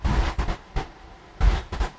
铁路列车》 《铁路列车》3
描述：编辑循环的火车声音。
Tag: 火车 节奏